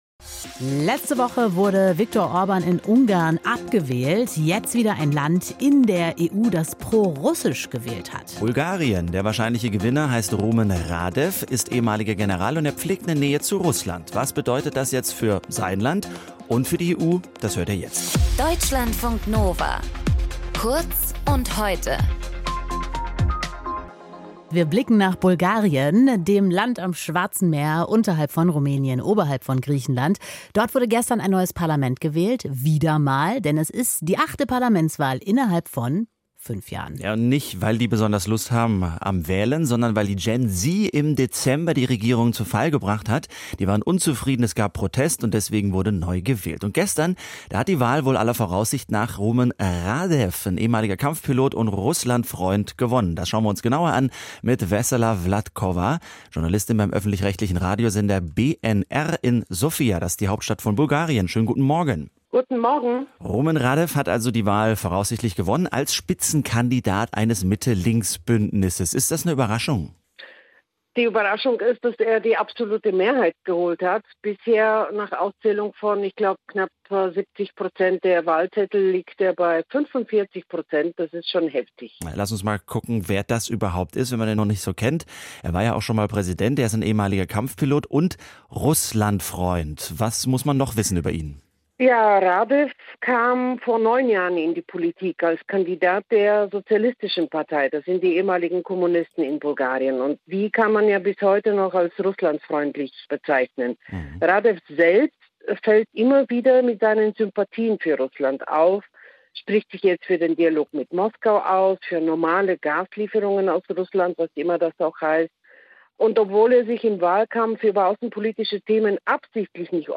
In dieser Folge mit: